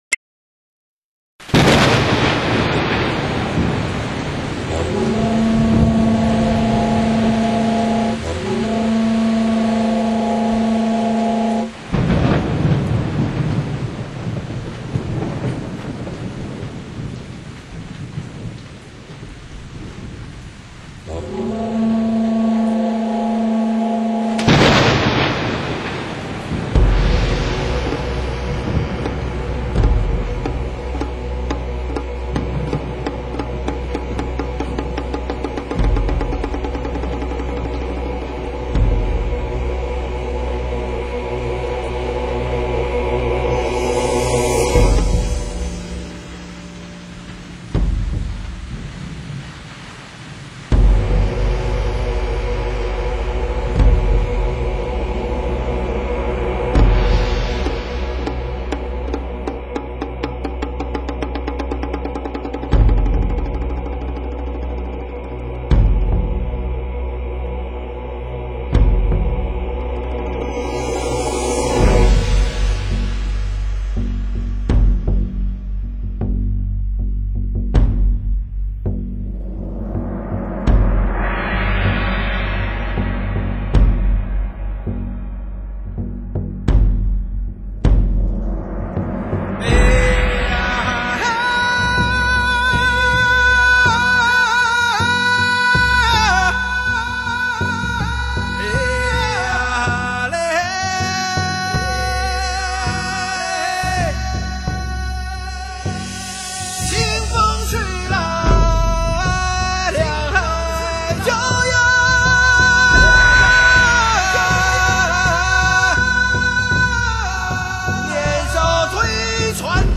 民族音乐
号子的歌唱方式，主要是“领、合”式，即一人领，众人合，或者众人领，众人合。